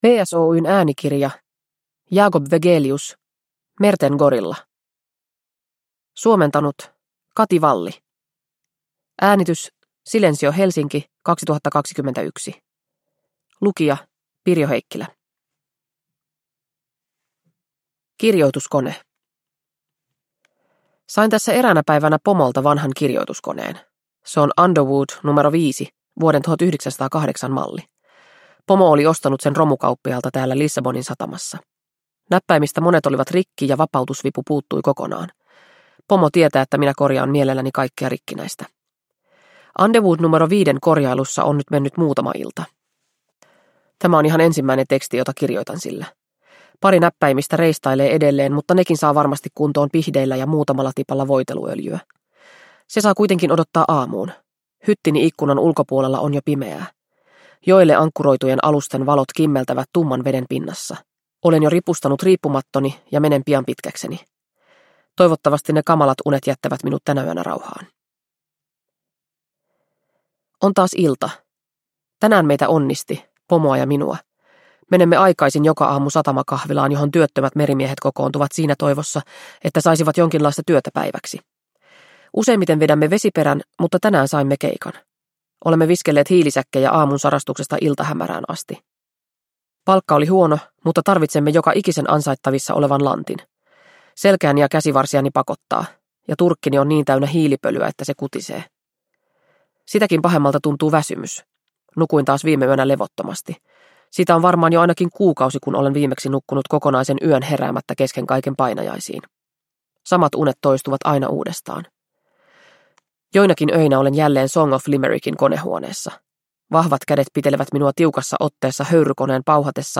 Merten gorilla – Ljudbok – Laddas ner